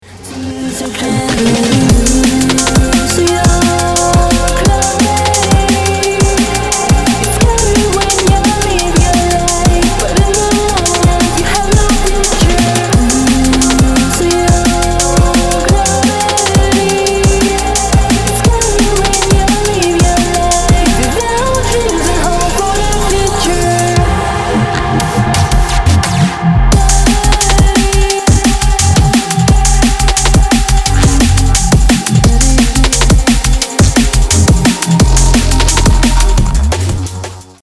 • Качество: 320, Stereo
громкие
мощные
красивый женский вокал
drum n bass